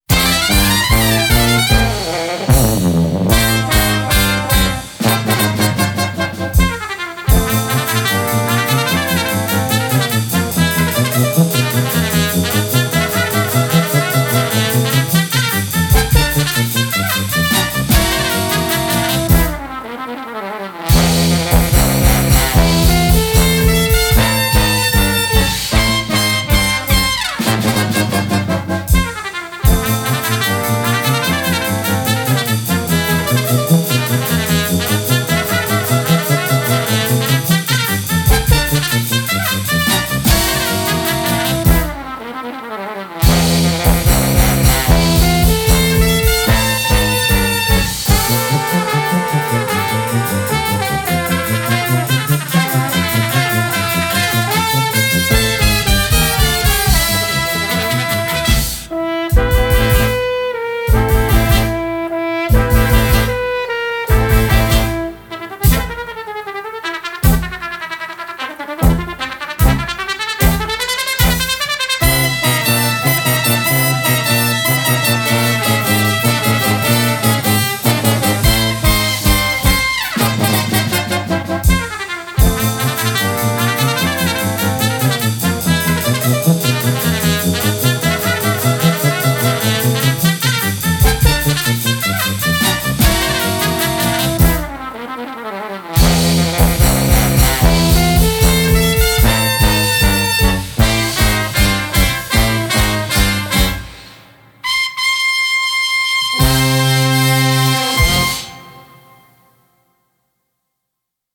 Genre: Swing, Jazz